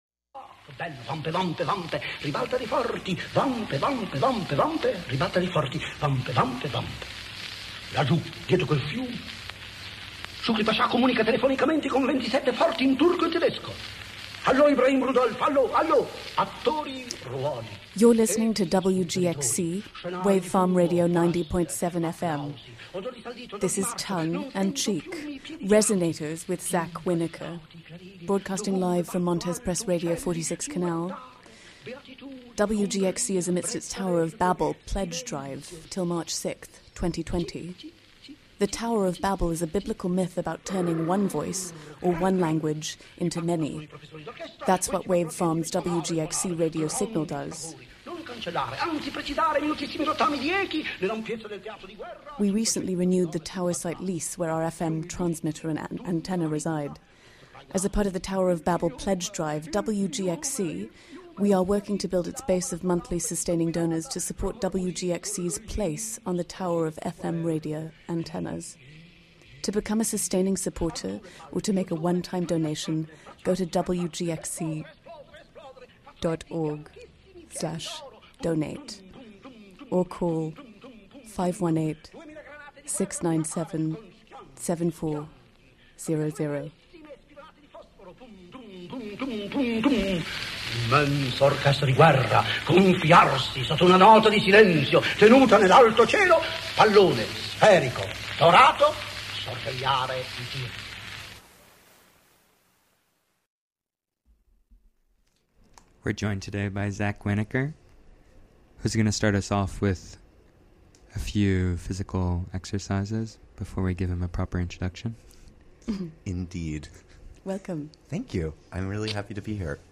(Vocalise) Play In New Tab (audio/mpeg) Download (audio/mpeg)